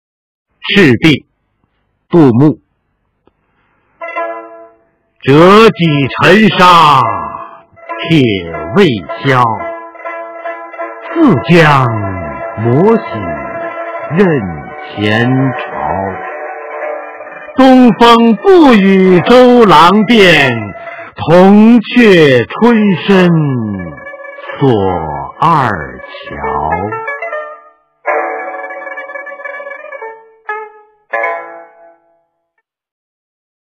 杜牧《赤壁》原文和译文（含赏析、朗读）　/ 杜牧